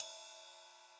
• '00s Long Ride Cymbal Sound Clip A Key 01.wav
Royality free ride sound sample tuned to the A note. Loudest frequency: 8548Hz
00s-long-ride-cymbal-sound-clip-a-key-01-FGI.wav